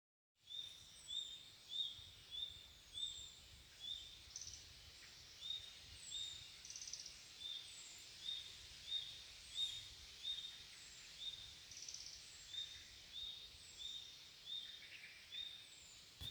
Birds -> Flycatchers ->
Red-breasted Flycatcher, Ficedula parva
StatusAgitated behaviour or anxiety calls from adults